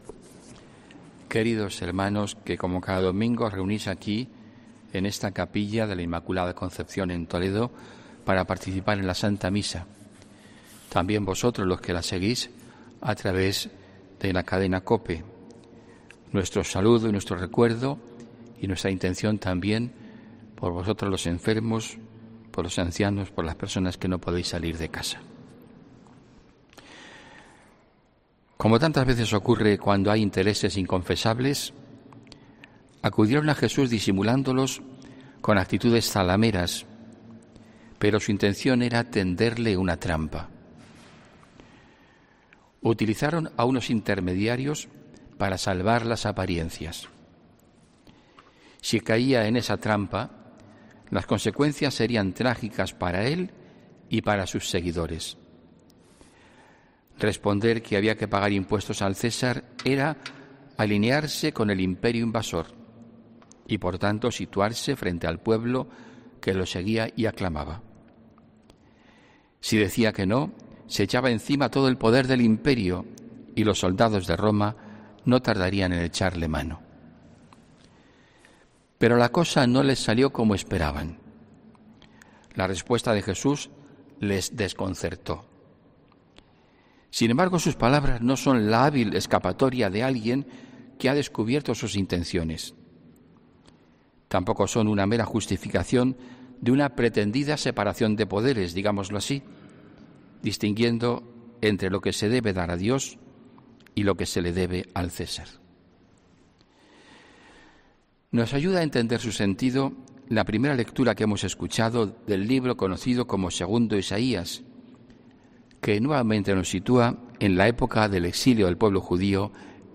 HOMILÍA 18 OCTUBRE 2020